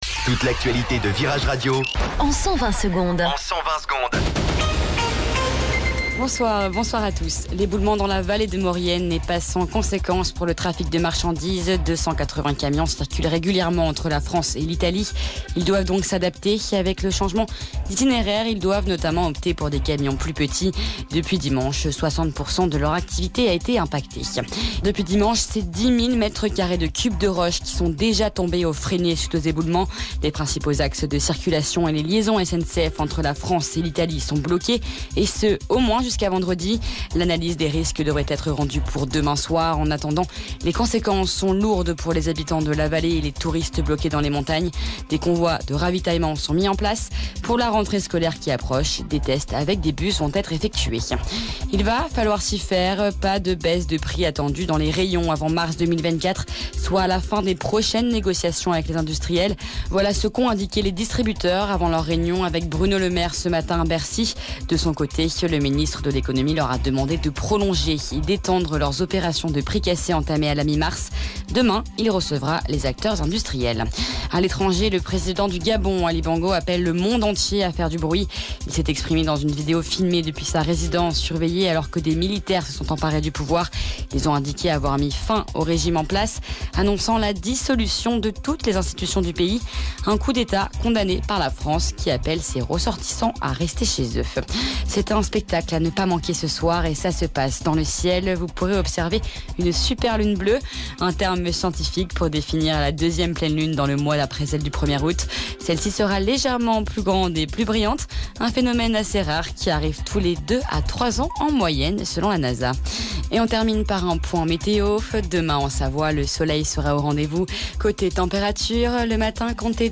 Flash Info Chambéry